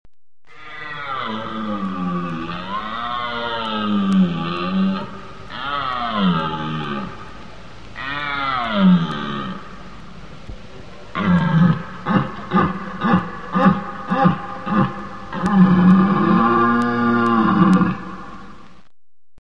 The bawls of the deer.